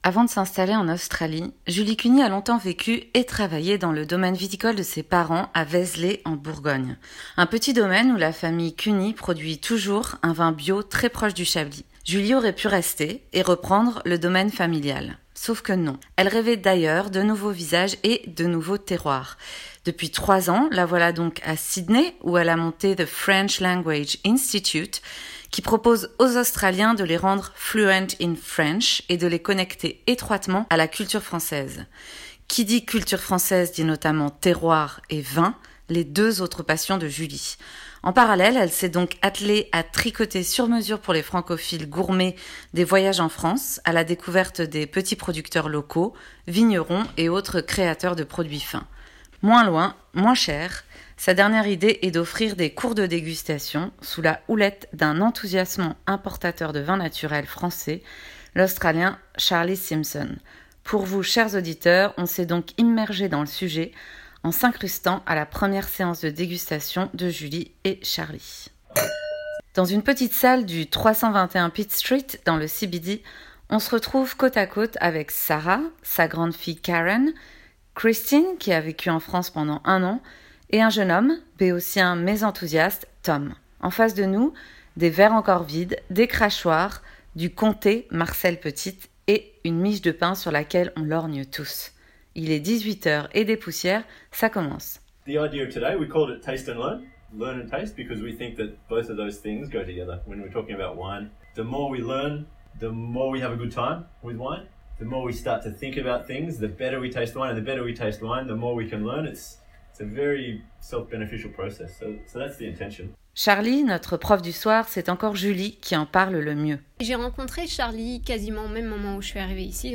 De l'art de goûter le vin (français) : reportage dans un cours de dégustation à Sydney
last-version-reportage-vin.mp3